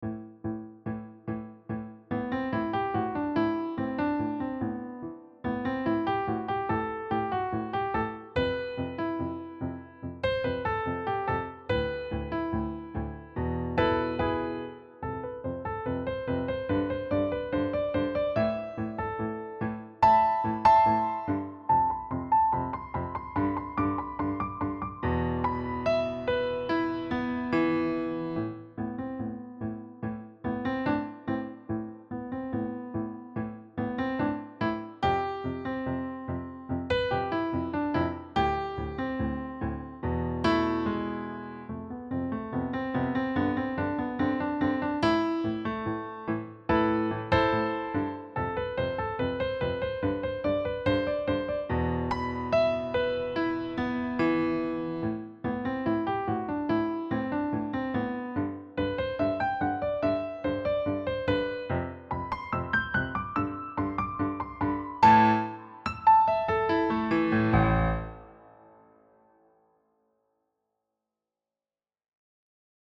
piano solo
Key: D Dorian & A Natural Minor
Time Signature: 4/4, BPM ≈ 144